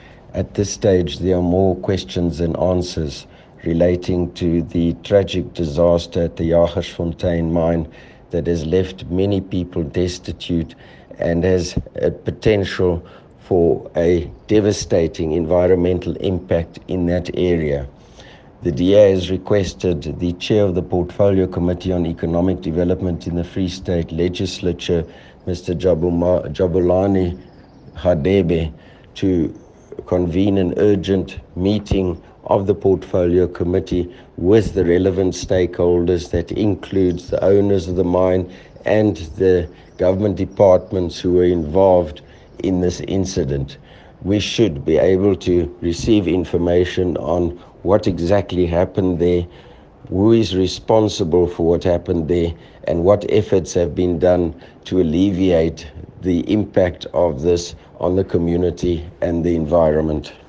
Afrikaans soundbites by Roy Jankielsohn MPL with images here, here, here, here and here.